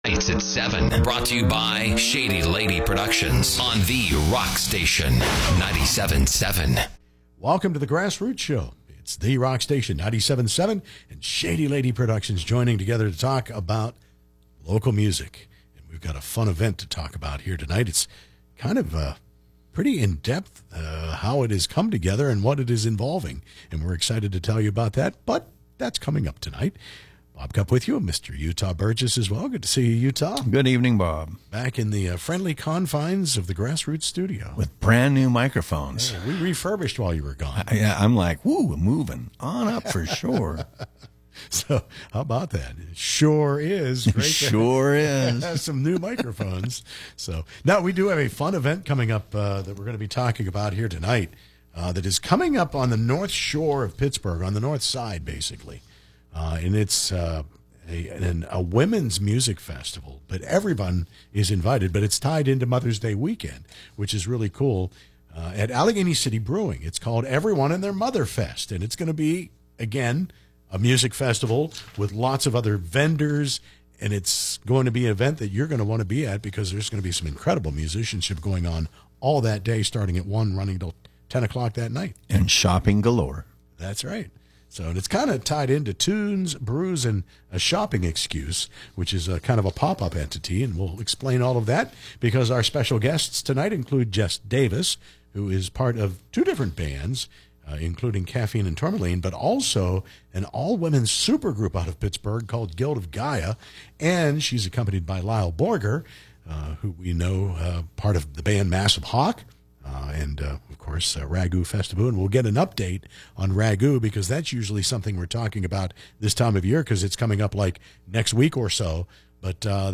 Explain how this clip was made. On tonight’s show, 7p EST on The Rock Station 97.7fm, we welcome to the studio